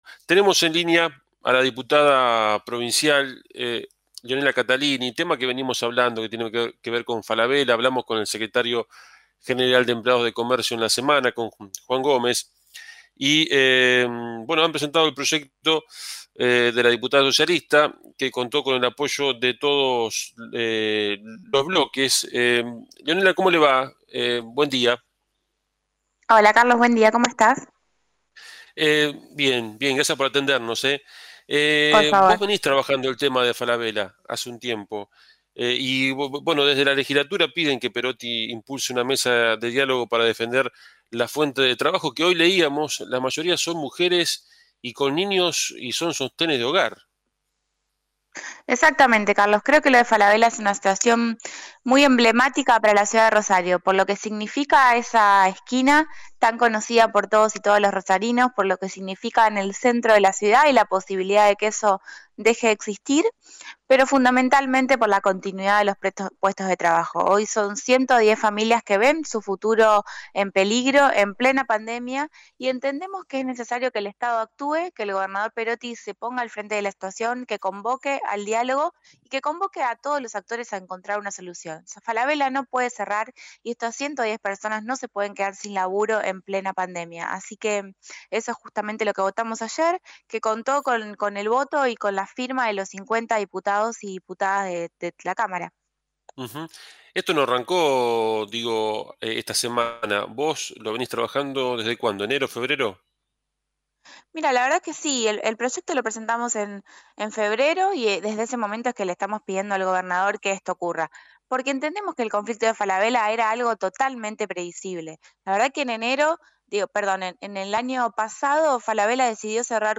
Compartimos la nota hecha en Buena Jornada